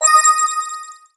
MenuEffect.wav